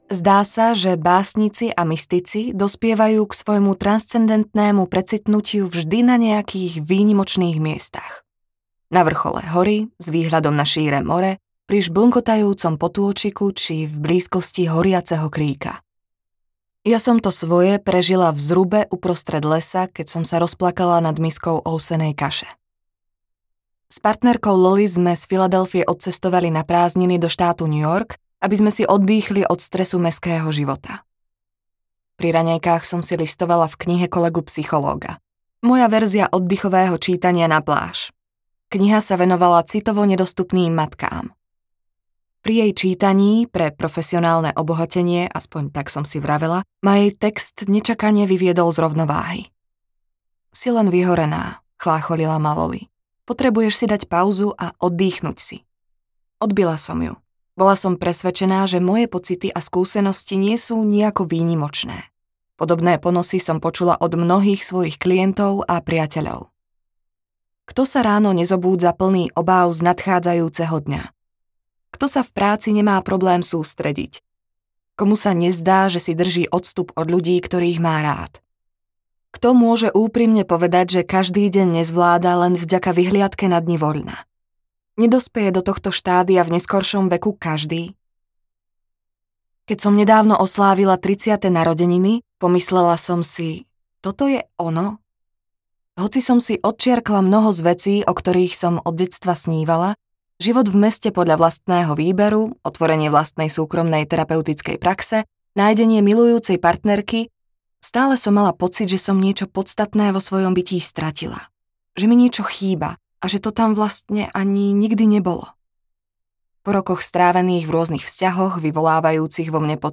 Odpracuj si svoje ja audiokniha
Ukázka z knihy